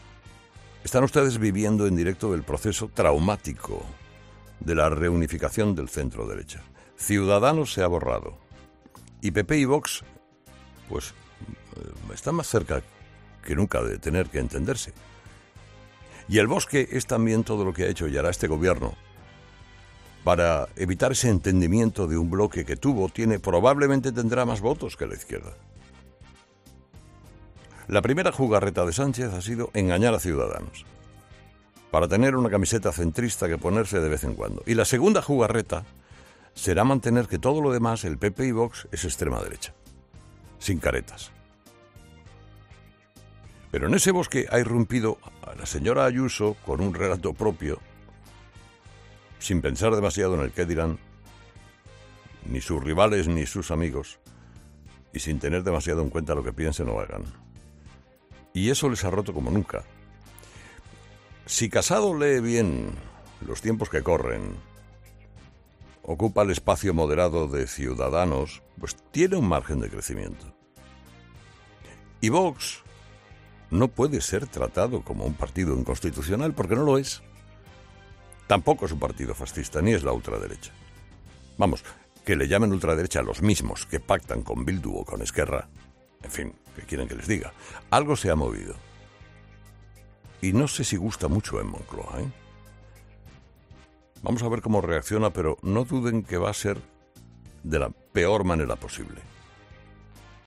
En su monólogo en 'Herrera en COPE', Herrera ha hecho la siguiente reflexión: